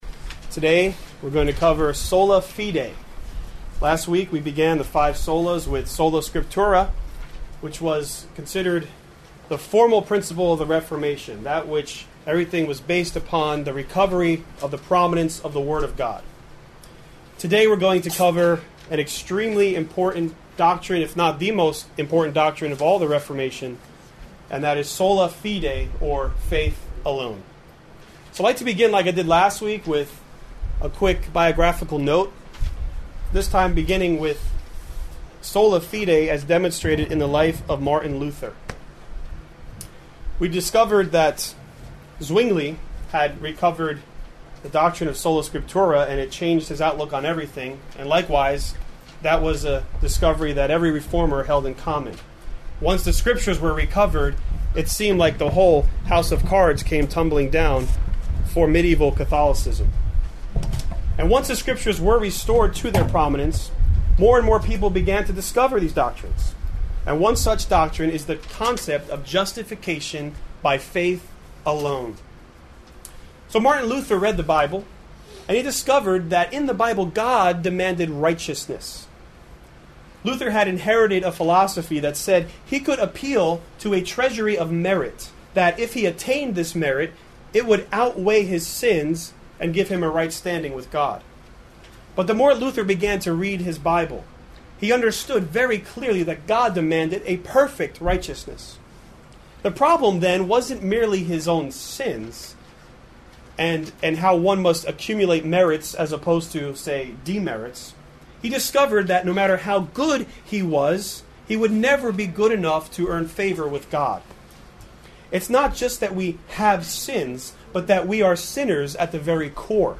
Sola Fide: Faith Alone | SermonAudio Broadcaster is Live View the Live Stream Share this sermon Disabled by adblocker Copy URL Copied!